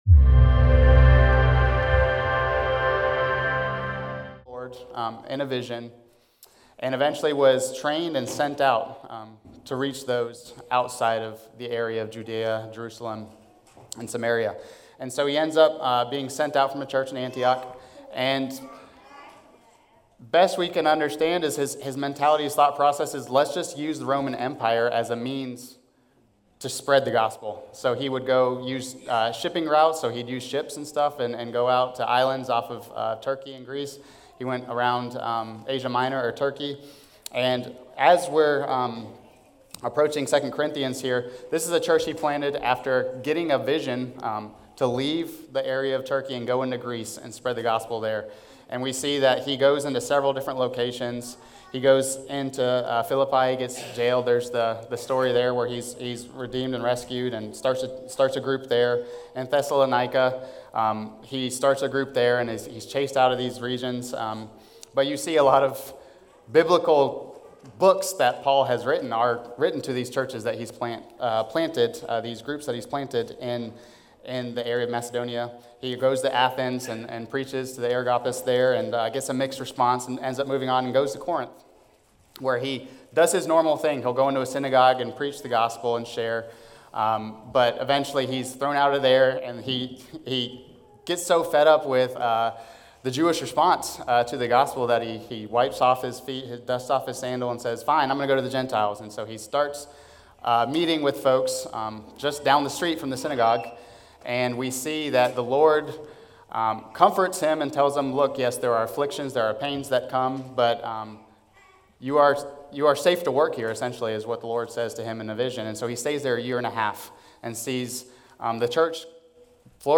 Sermons recorded during the Sunday morning service at Corydon Baptist Church in Corydon, Indiana